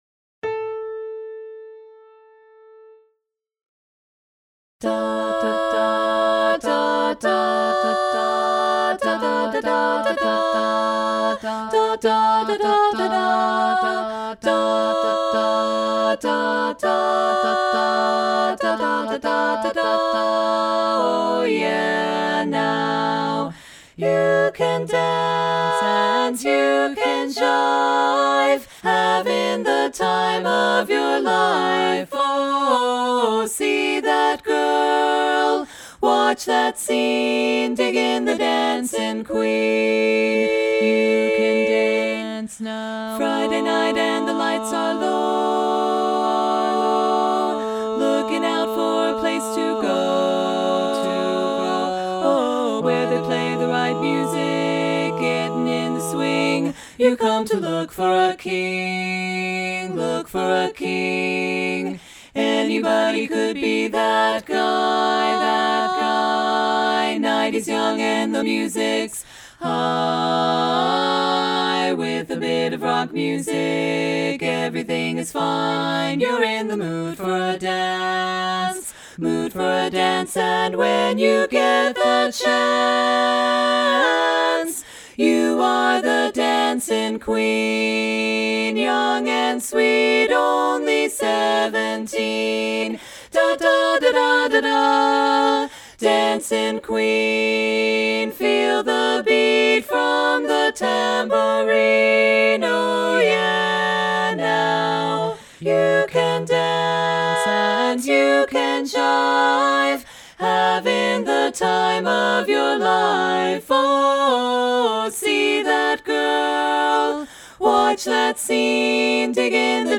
Genre: Learning Track.